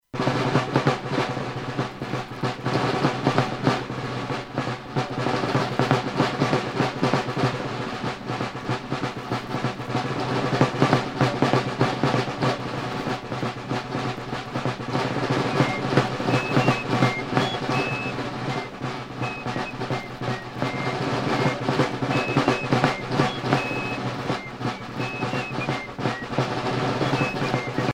Airs de fifres de l'Entre Sambre et Meuse
Pièce musicale éditée